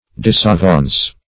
Search Result for " disavaunce" : The Collaborative International Dictionary of English v.0.48: Disavaunce \Dis`a*vaunce"\, v. t. [Cf. Disadvance .] To retard; to repel; to do damage to.